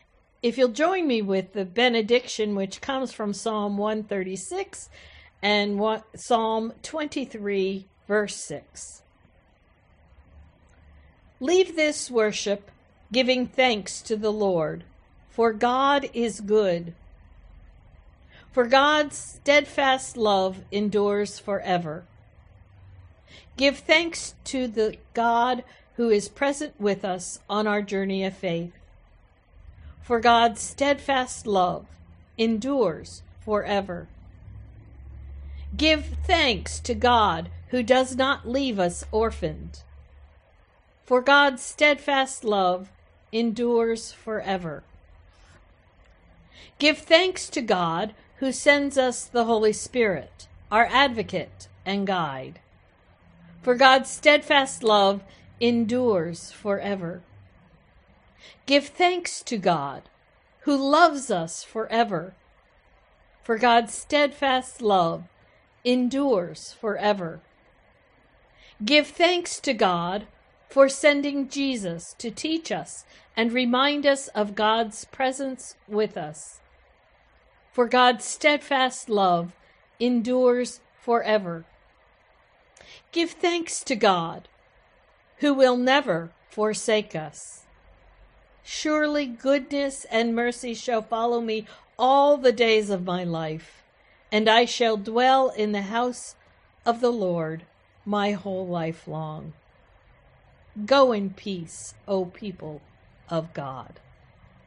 Closing Hymn: No. 364 Because He Lives